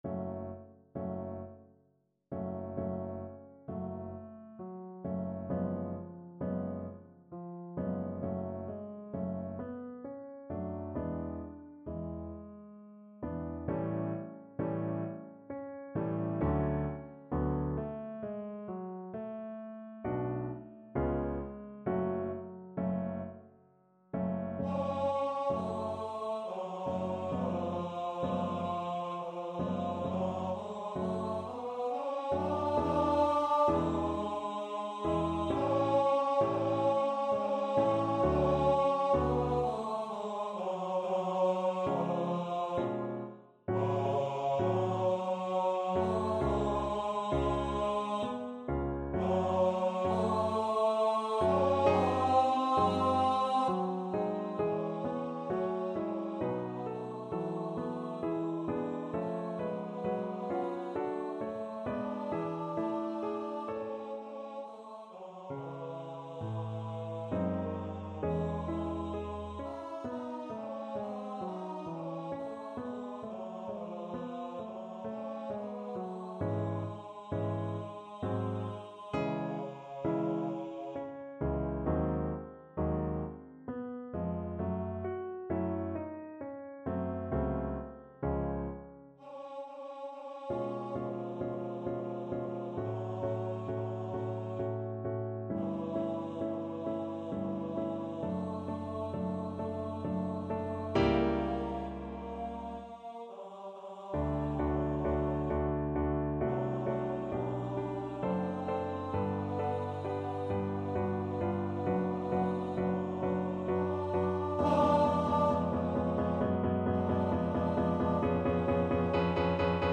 3/4 (View more 3/4 Music)
~ = 100 Adagio =66
Classical (View more Classical Bass Voice Music)